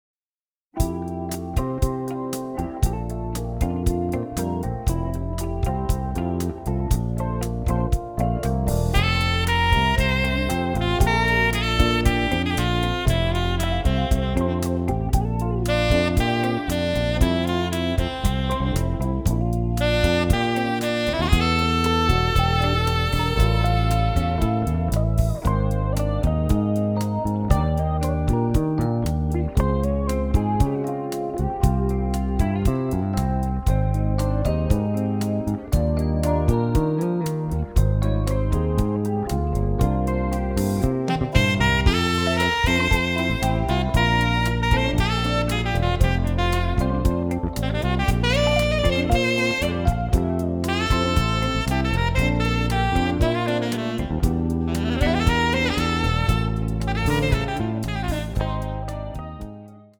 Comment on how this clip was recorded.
All tracks stereo, except * mono